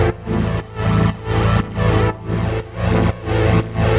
GnS_Pad-MiscA1:4_120-A.wav